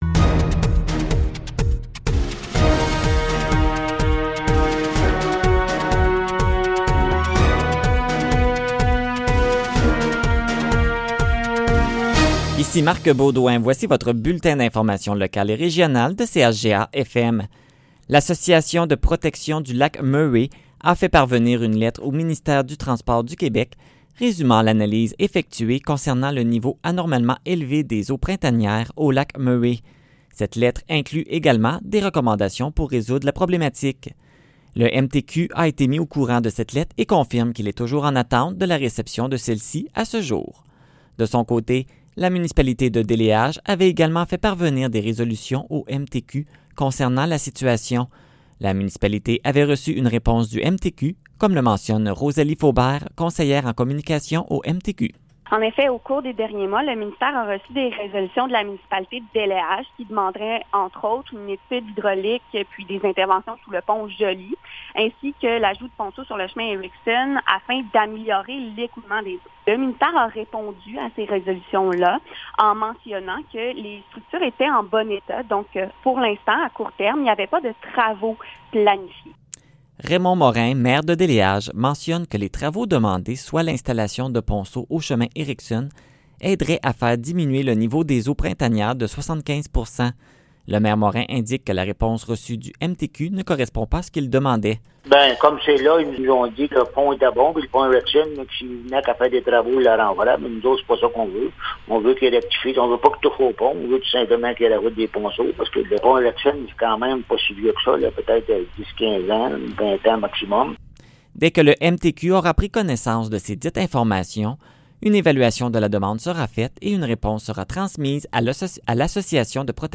Nouvelles locales - 14 octobre 2020 - 15 h